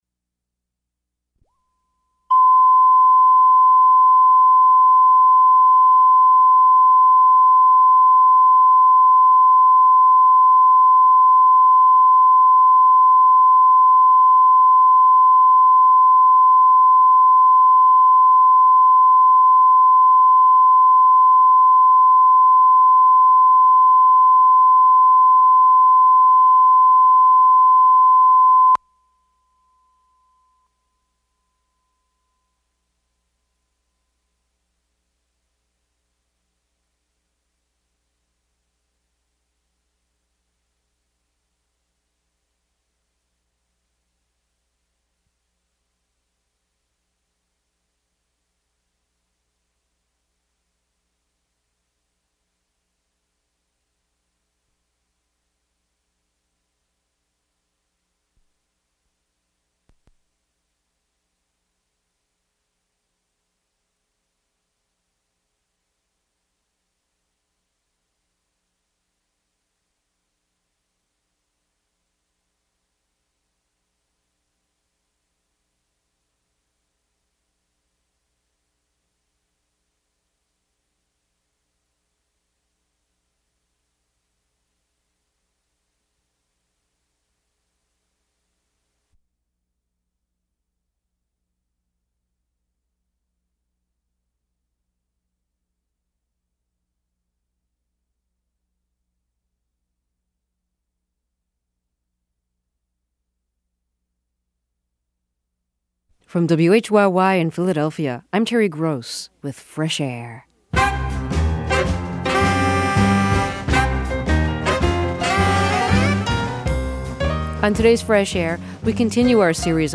(Rebroadcast)